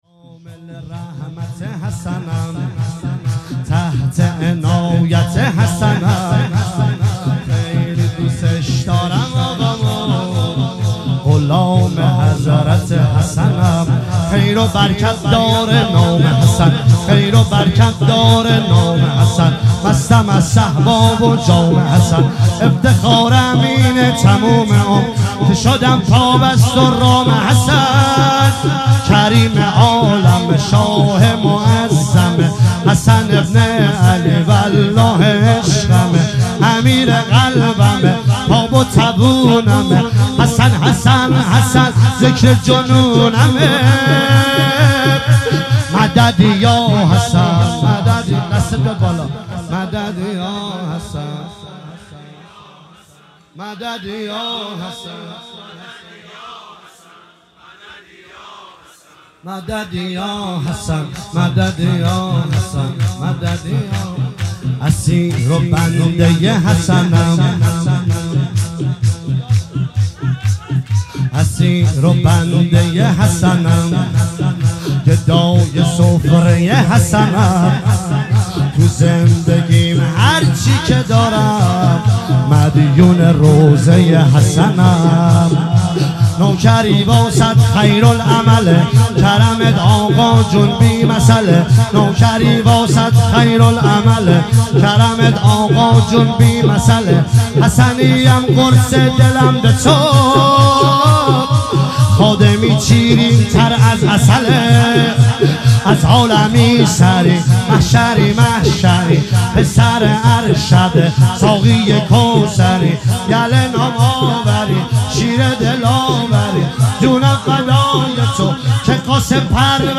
مراسم شب پنجم محرم ۱۳۹۷
هروله شامل رحمت حسنم